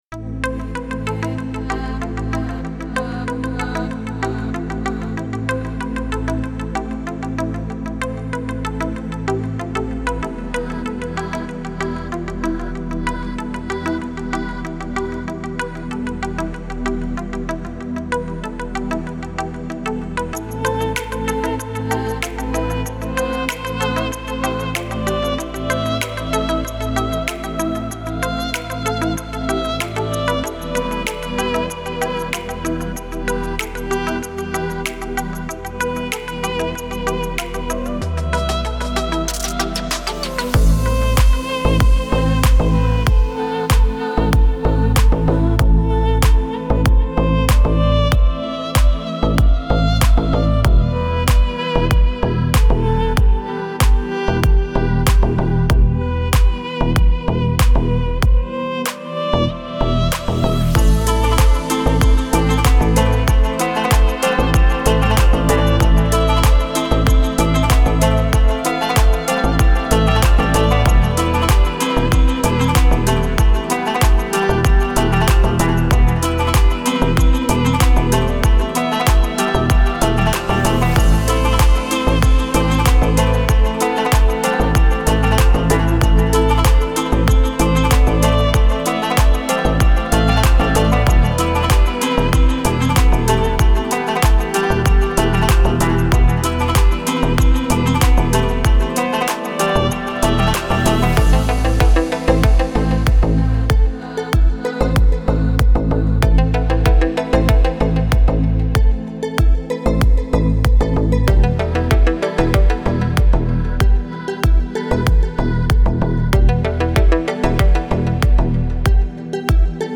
موسیقی بی کلام ریتمیک آرام
موسیقی بی کلام اورینتال